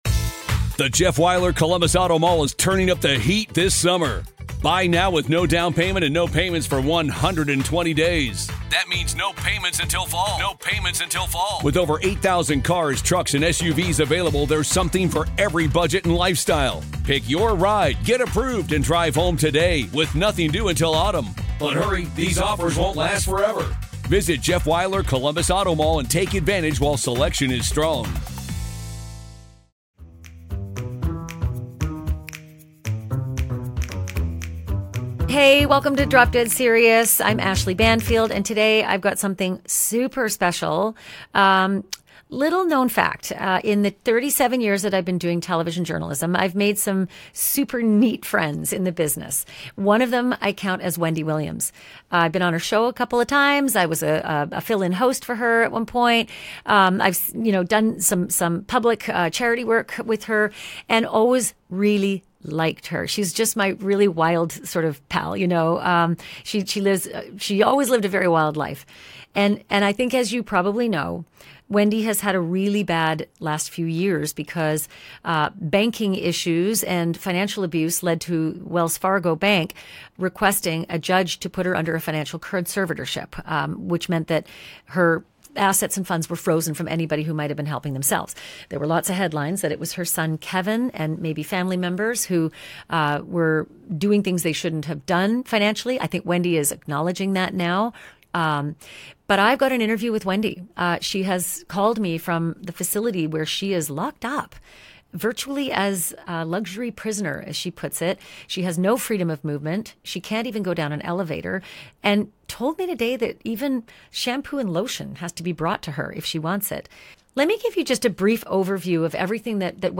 EXCLUSIVE INTERVIEW: Wendy Williams Calls In & Drops New Bombshells